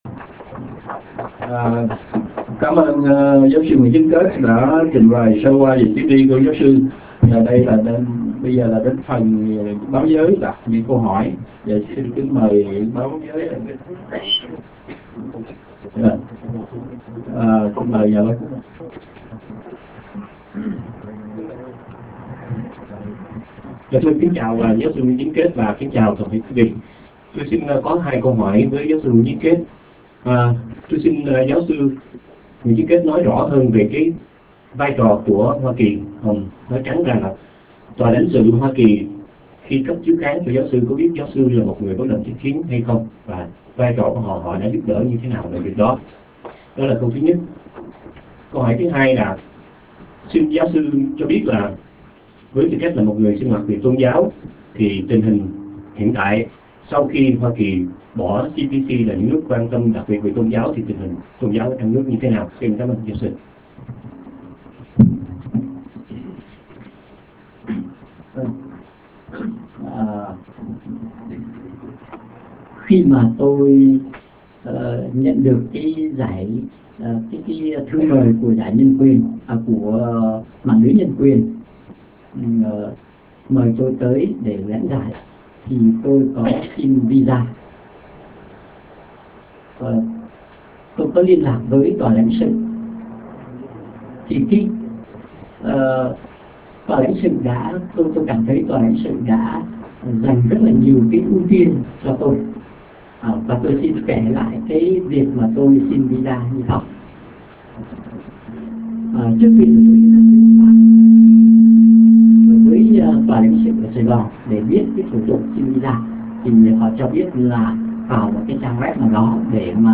Buổi họp b�o